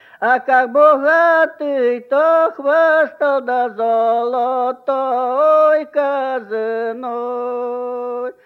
Оканье (полное оканье, свойственное Поморской группе севернорусского наречия – это различение гласных фонем /о/ и /а/ во всех безударных слогах)